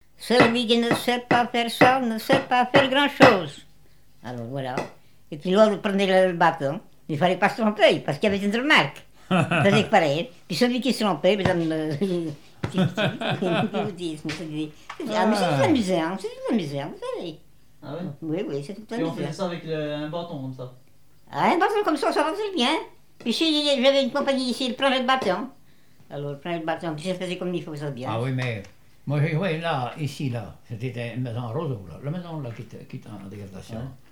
Couplets à danser
Pièce musicale inédite